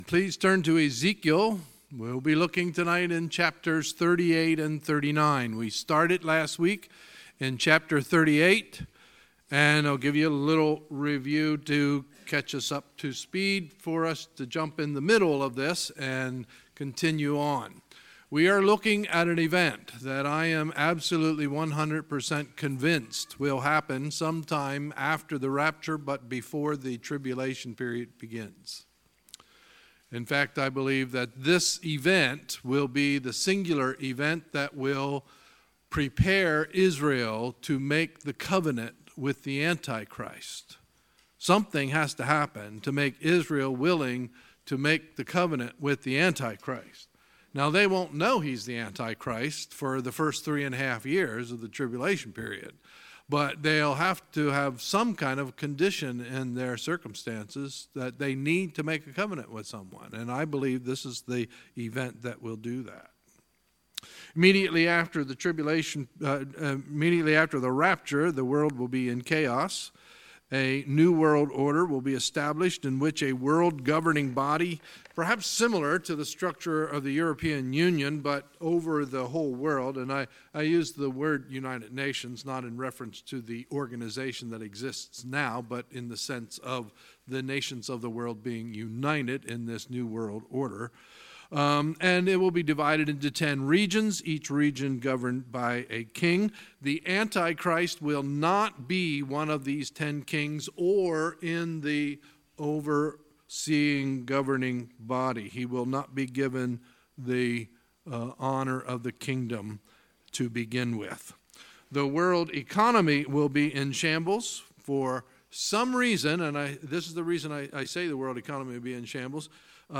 Sunday, September 9, 2018 – Sunday Evening Service